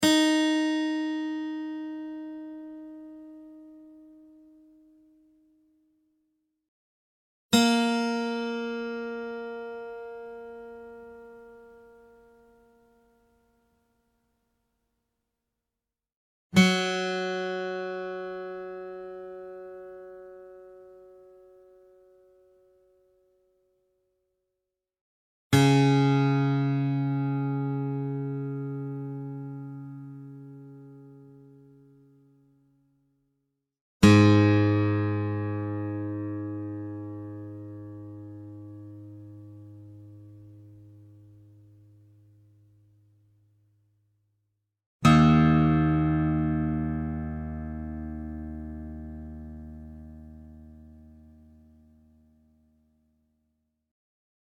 Real acoustic guitar sounds in Half Step Down Tuning
Guitar Tuning Sounds